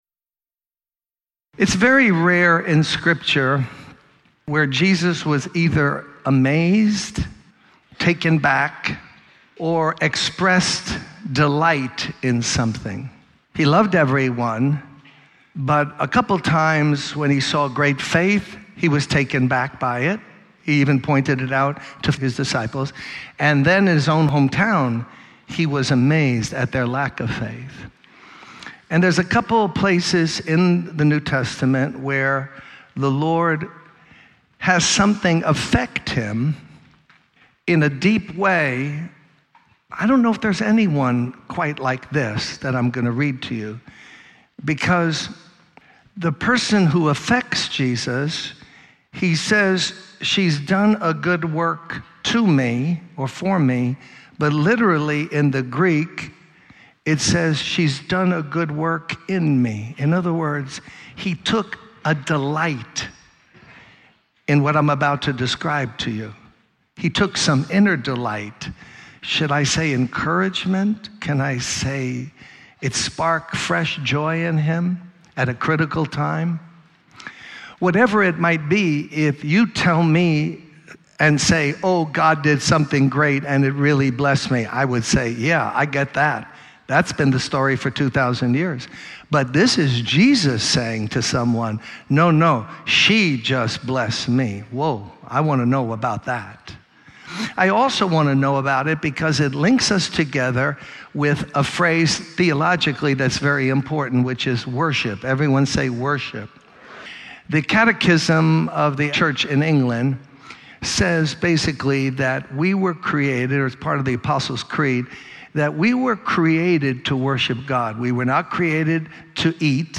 In this sermon, the preacher discusses the importance of true worship and the dangers of turning it into a ritual. He emphasizes that being in the presence of God is essential for conviction of sin.